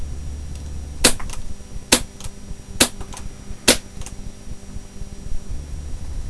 いくつかの銃の発射音を録音してみました。
マイクを銃の側面近くに設置したので、全体的に発射音より作動音が目立つことになってしまっています。
実際、フィールドで人間の耳での聞こえ方とは、かなりの違いがあるように感じられますが参考までに。
コクサイ・Ｍ１６（サイレンサー無し）